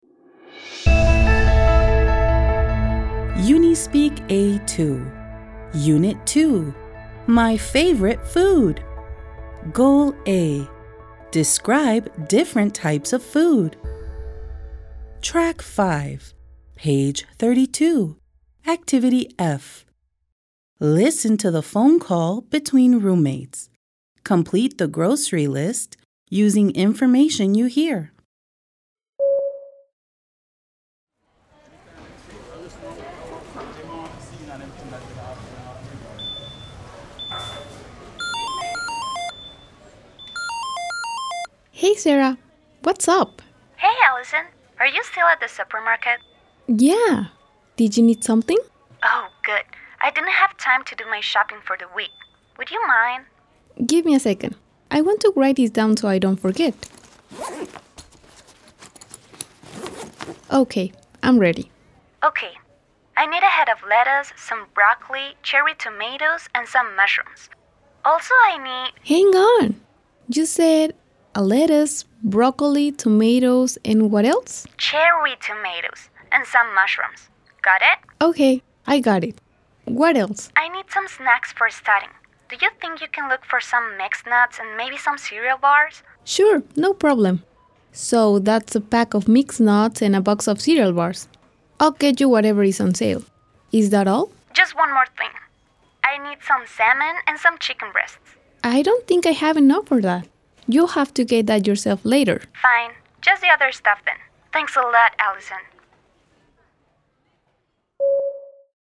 Listen to the phone call between roommates. Complete the grocery list using information you hear.